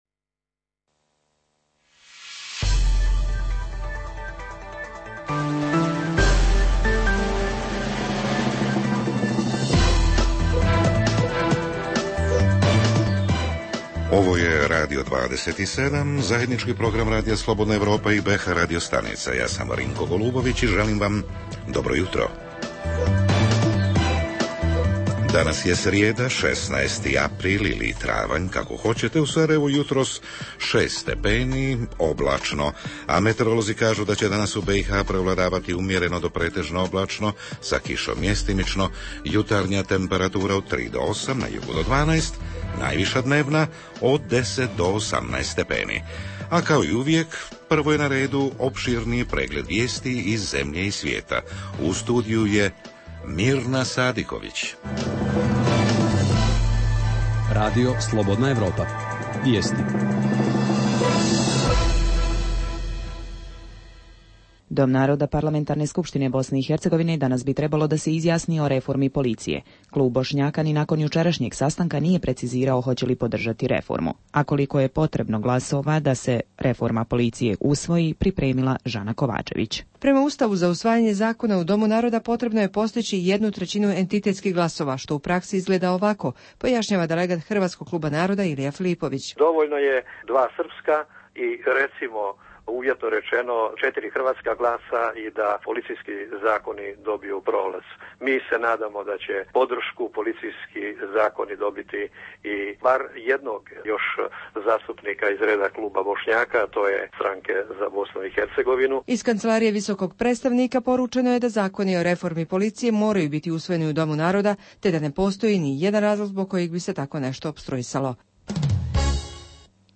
Jutarnji program za BiH koji se emituje uživo, a govorimo o kampanji "Čisto je lijepo", koju promovira USAID, a čiji je cilj da putem akcija uklanjanja smeća i prikupljanja otpada za reciklažu poboljša izgled okoline. Kampanja je do sada okupila 17 komunalnih preduzeća iz BiH, a uključena su i državna i entitetska ministarstva, kao i javni servisi entiteta.
Redovni sadržaji jutarnjeg programa za BiH su i vijesti i muzika.